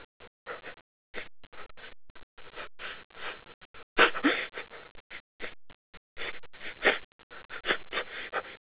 crying_loop1.wav